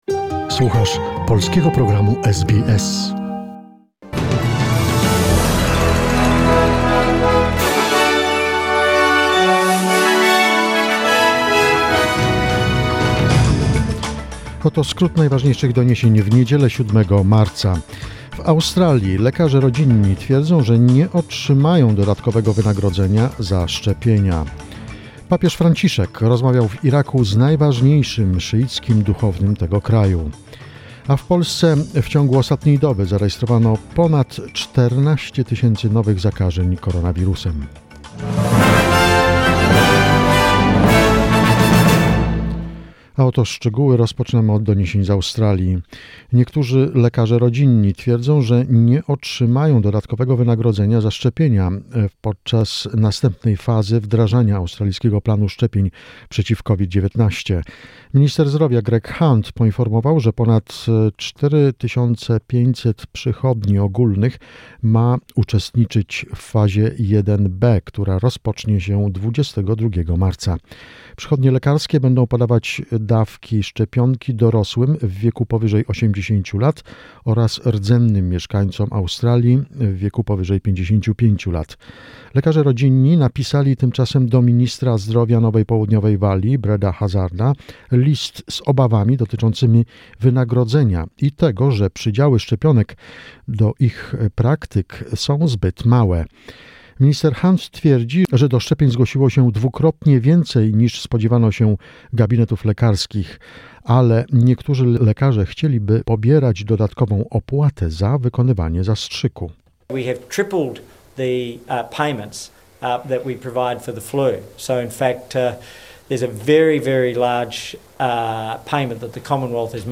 SBS News, 7 March 2021